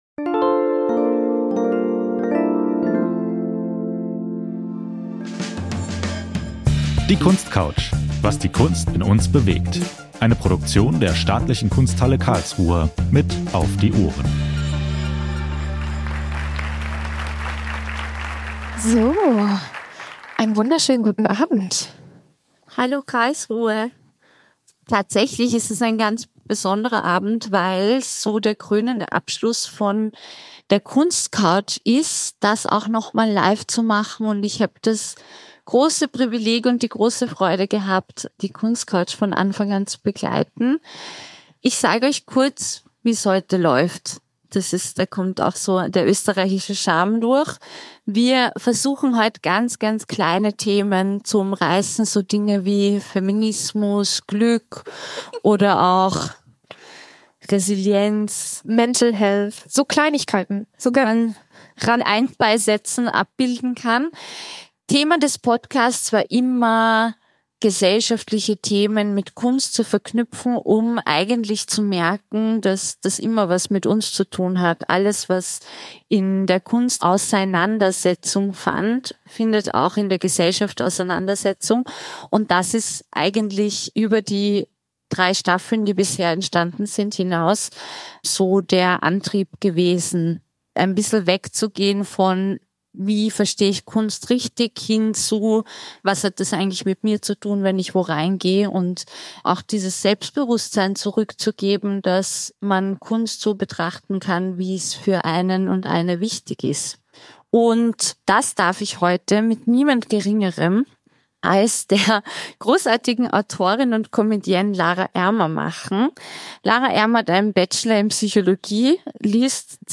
Zwischen Alltag und Leinwand – Liveaufzeichnung der Kunstcouch ~ Kunstcouch Podcast
Die Folge wurde bei einem Live-Event aufgezeichnet und bildet zugleich den Abschluss des Podcasts.